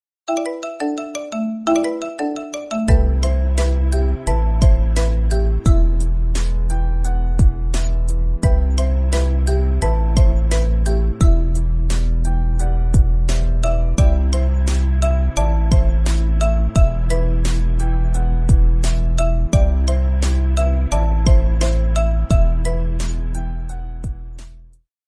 Genres: Iphone (19) - Marimba (20)